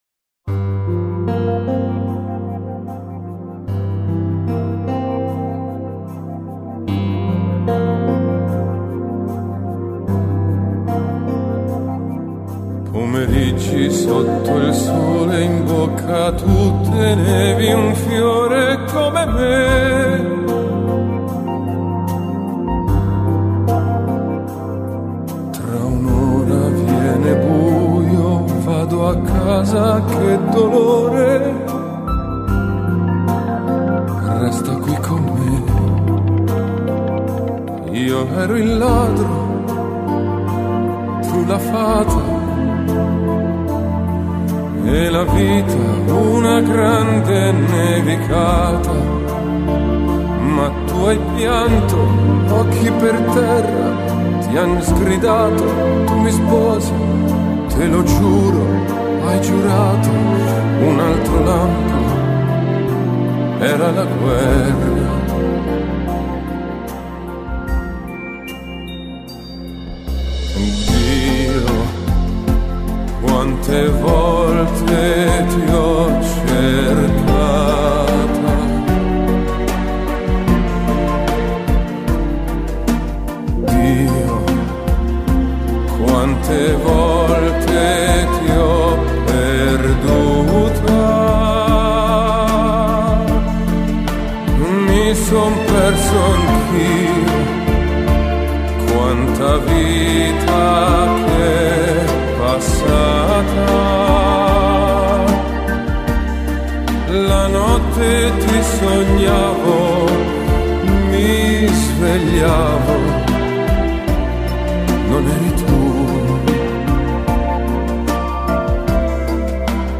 童真浪漫的美梦 结合独特繁复的管弦乐 提拉出跨越时代的流行新感受